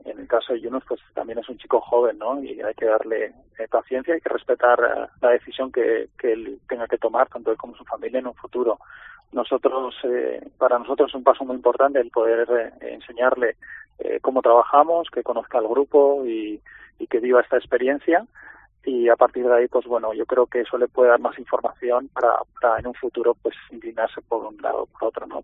Desde la concentración norteamericana nos cuenta cómo fue